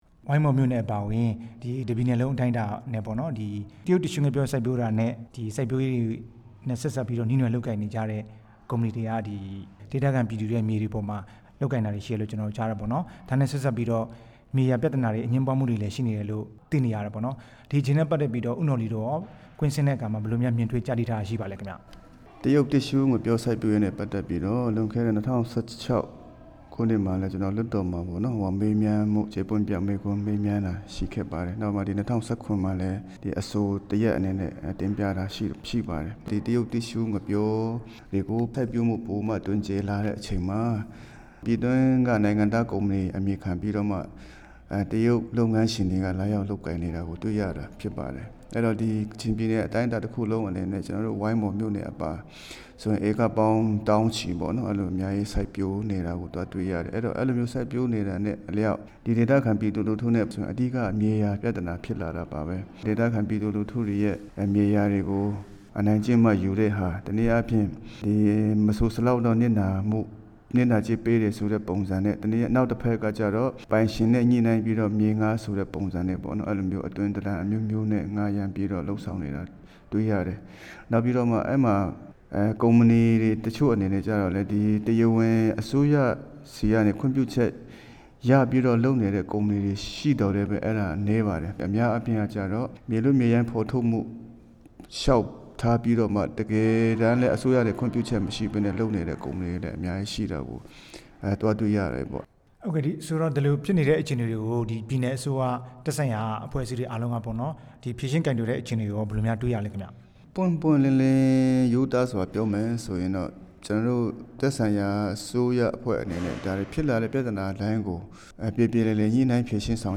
ကချင် မြေယာပြဿနာအကြောင်း မေးမြန်းချက်